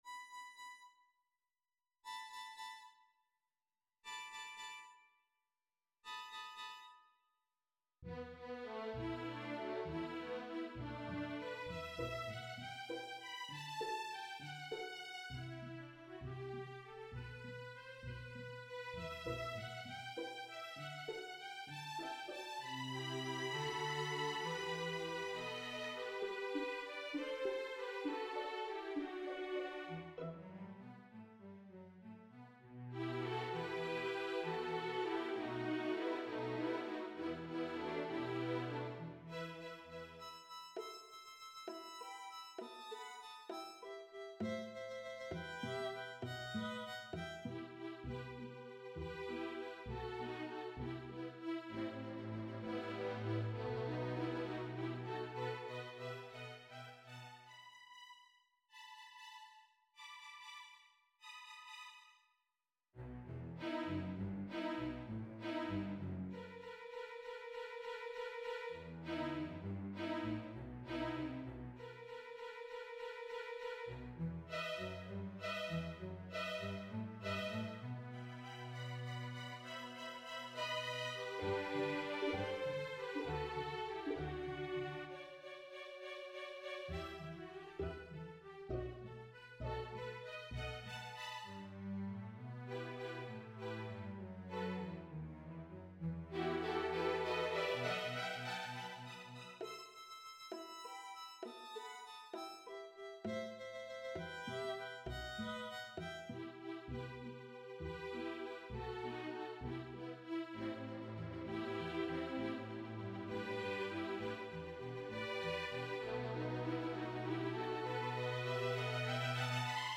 for String Orchestra (2017)
Root position chords and consonant sonorities are prevalent.